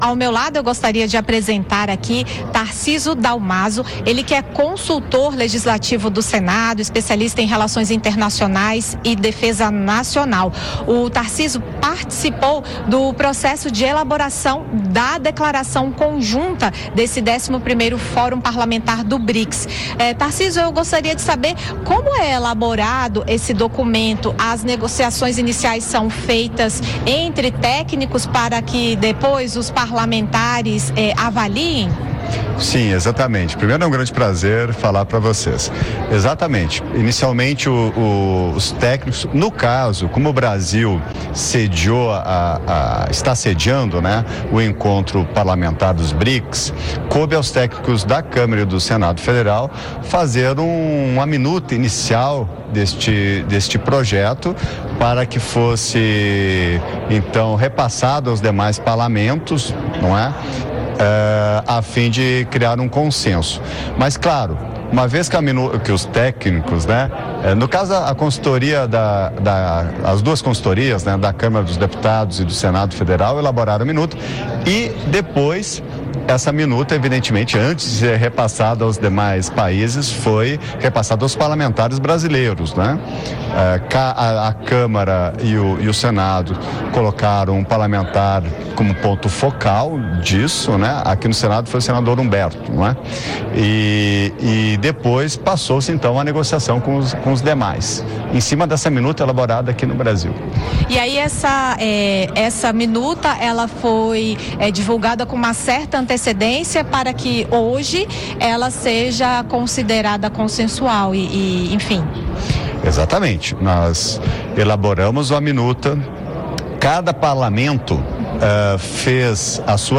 Em entrevista à Rádio Senado